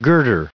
added pronounciation and merriam webster audio
1570_girder.ogg